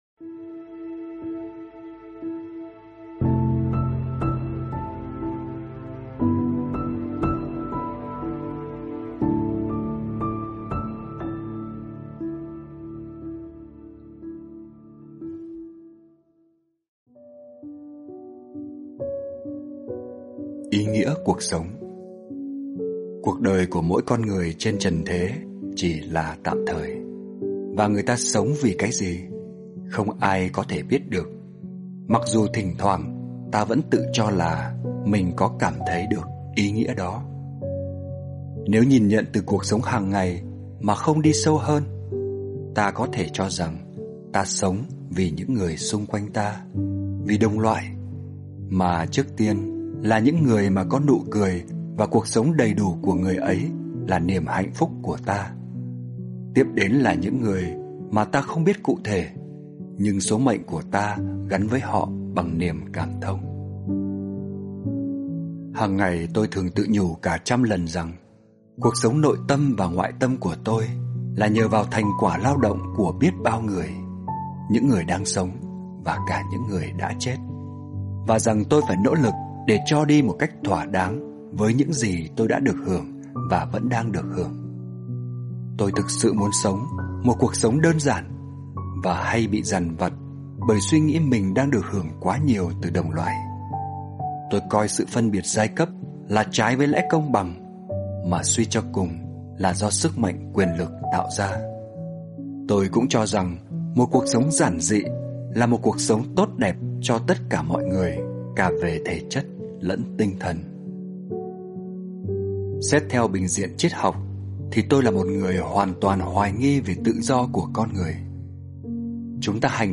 Sách nói mp3